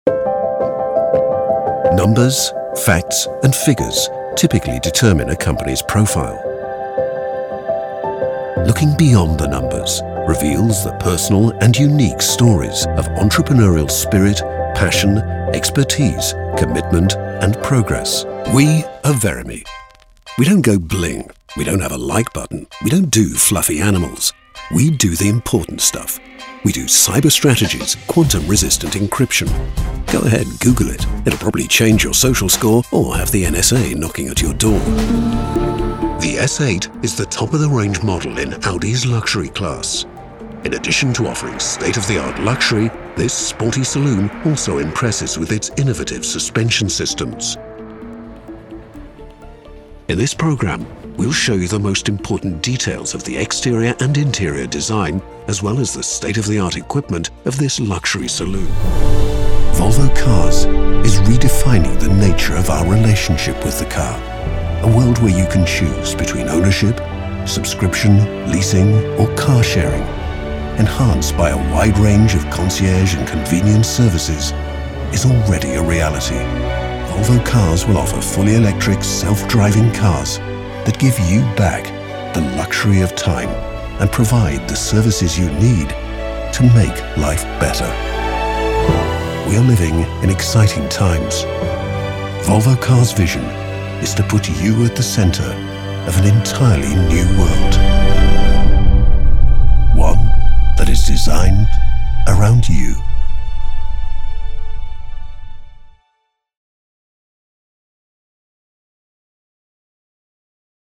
Corporate narration demo
Contemporary British English; English RP; London; Yorkshire; Liverpool; Scottish; West Country; Various European; Standard American
Middle Aged